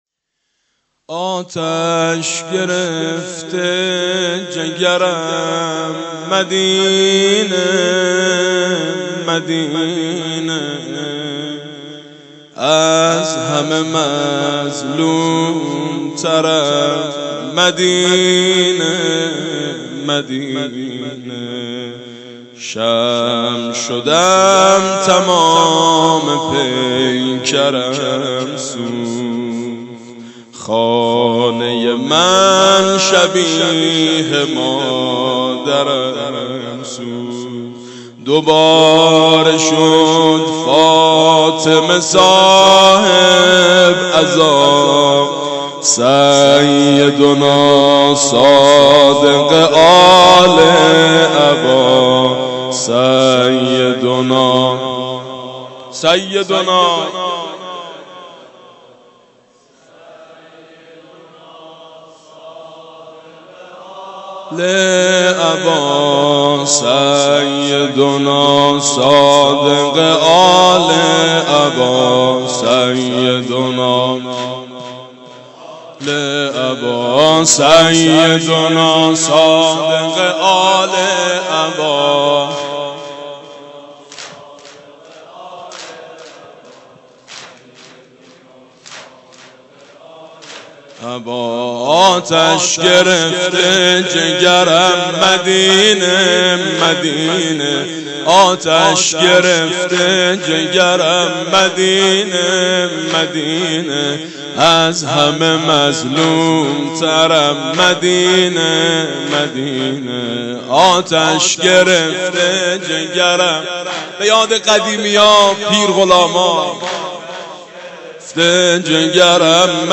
«شهادت امام صادق 1390» زمینه: آتش گرفتم جگرم مدینه